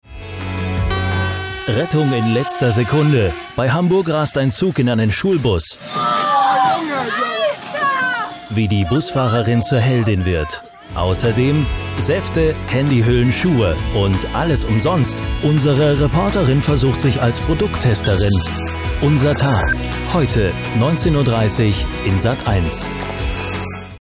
Kein Dialekt
Sprechprobe: Werbung (Muttersprache):
stimmprobe_sendetrailer-sat1.mp3